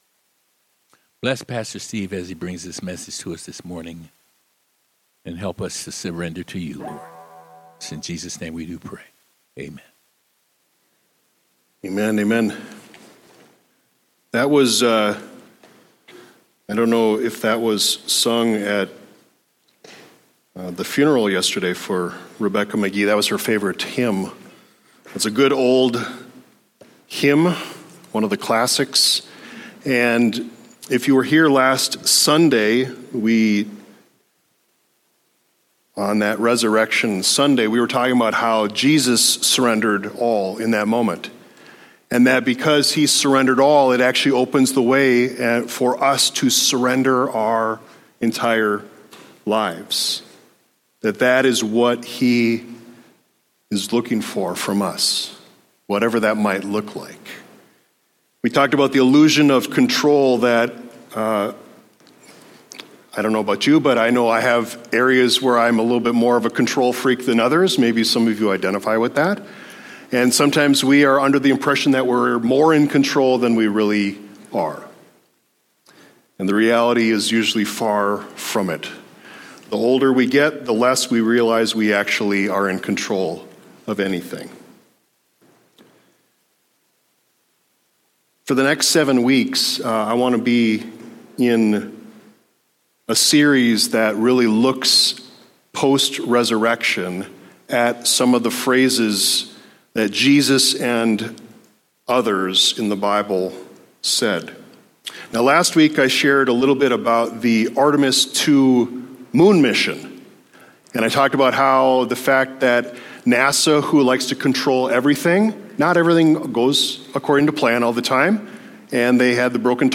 Download Posted in sermons